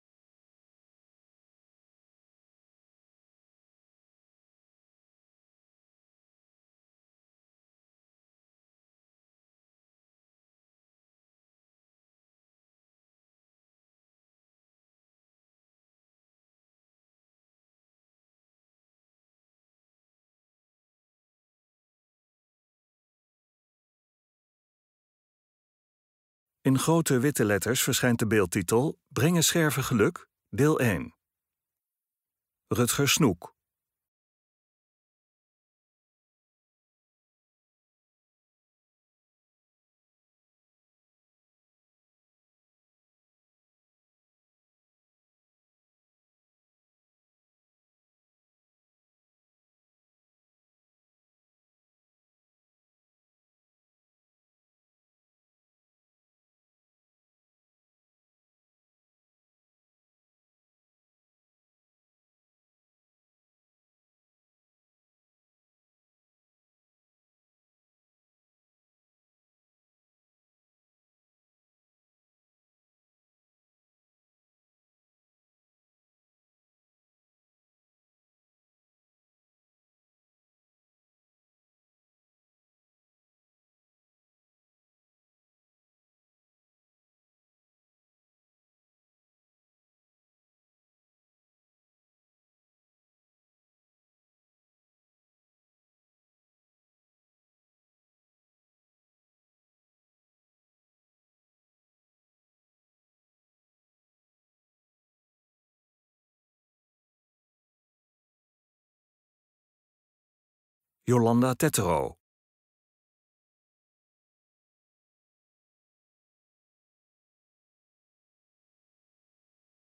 MYSTIEKE MUZIEK TOT HET EIND VAN DE VIDEO